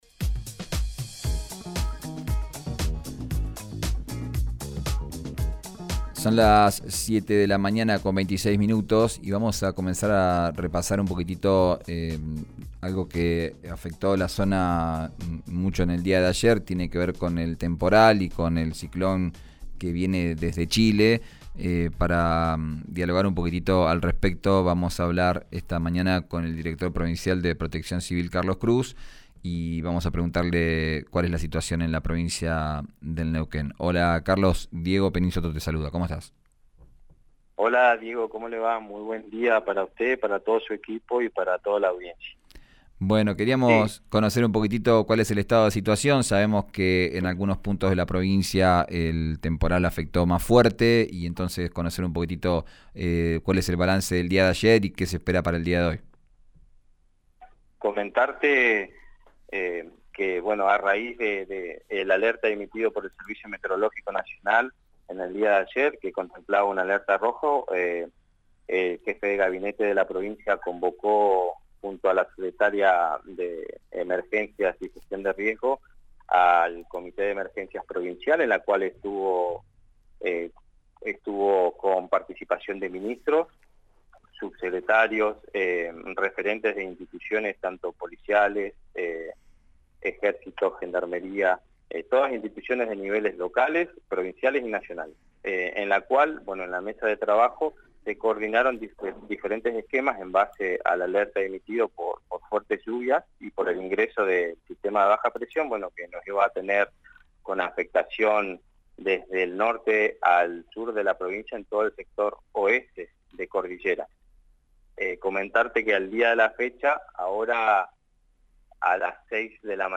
Desde ayer, las condiciones climáticas disminuyen, sin embargo, el director provincial de Protección Civil, Carlos Cruz, dijo a RÍO NEGRO RADIO que continúan las precipitaciones y con ellas los trabajos y asistencias en la cordillera.